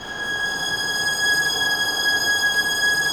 Index of /90_sSampleCDs/Roland - String Master Series/STR_Vlns Bow FX/STR_Vls Sordino